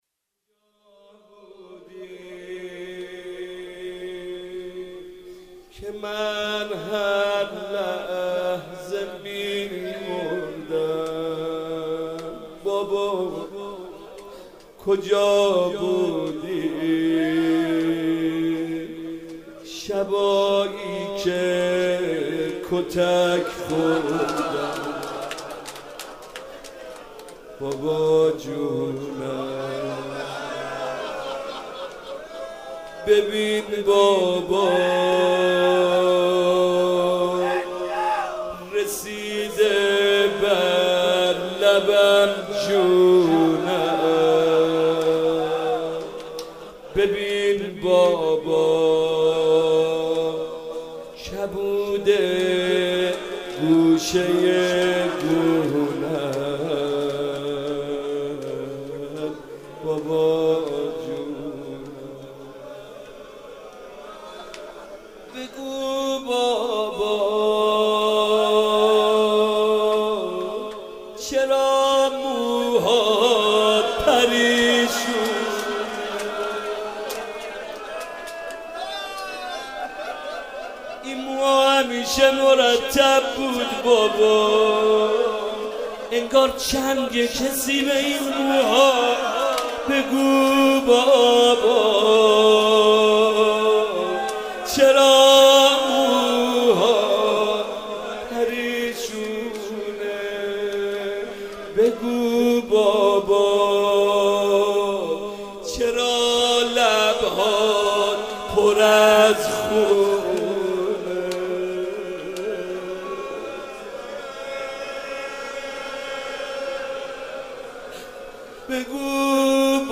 روضه
مناسبت : شب سوم رمضان